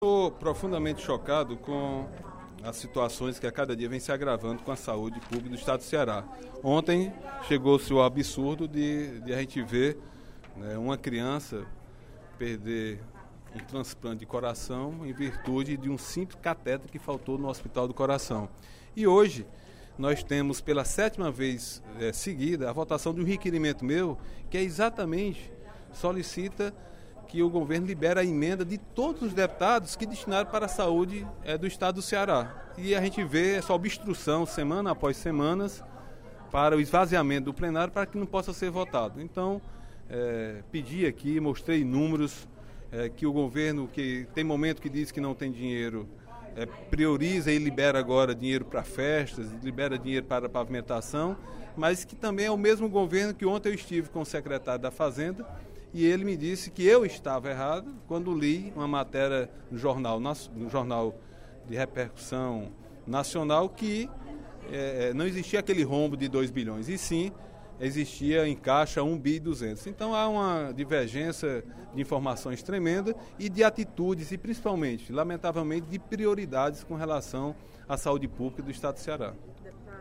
O deputado Agenor Neto (PMDB) cobrou, durante o primeiro expediente da sessão plenária desta quinta-feira (07/07), a aprovação das emendas dos parlamentares que direcionam recursos para a saúde pública do Estado.